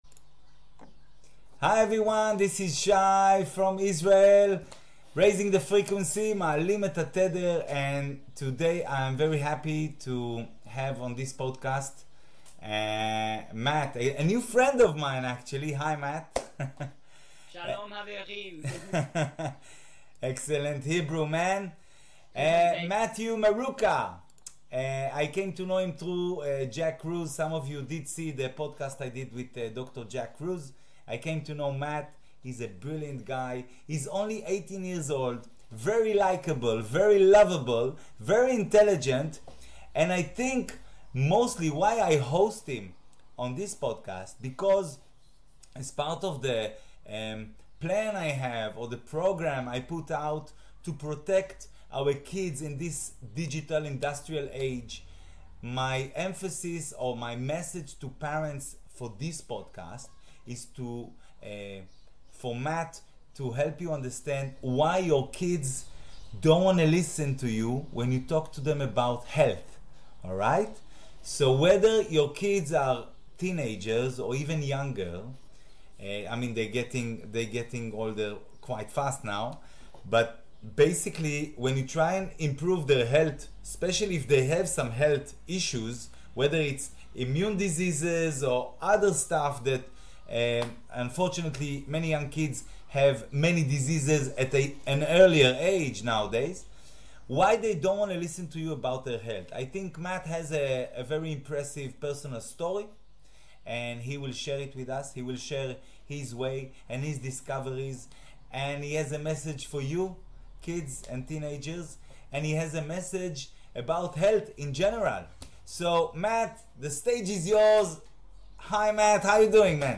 ראיון
interview